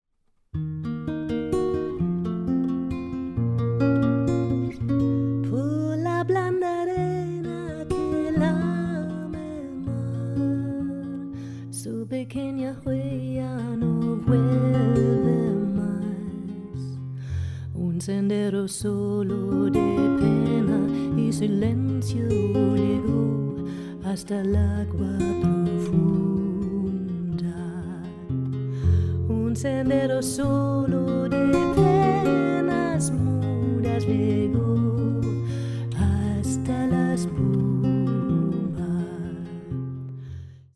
Esta grabación se realizó en el estudio de Halle.
guitarra